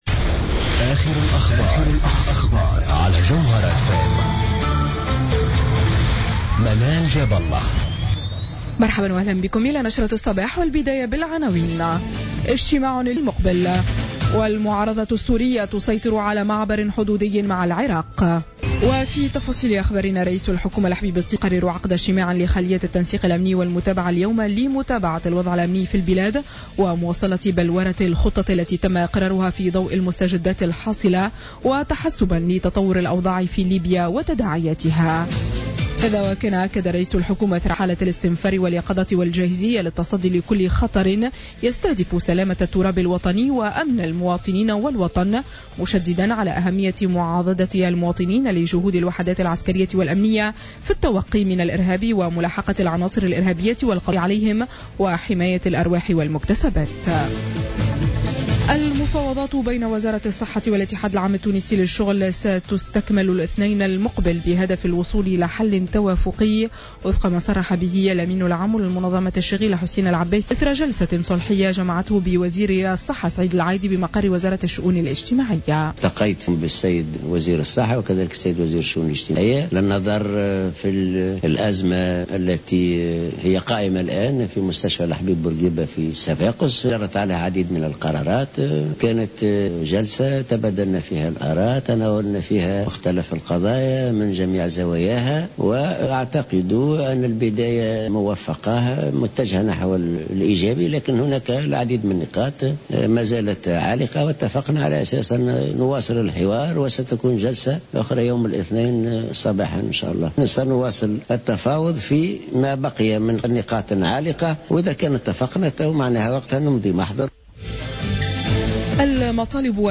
نشرة أخبار السابعة صباحا ليوم السبت 5مارس 2016